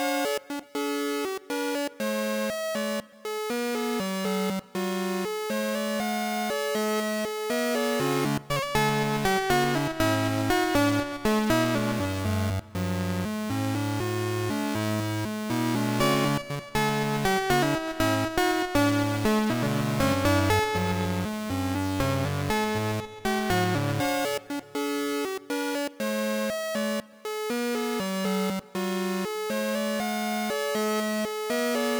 Happy Passtime Chiptune
A Verry Small Happytune, maybe useful for Village shop, or home kind of setting.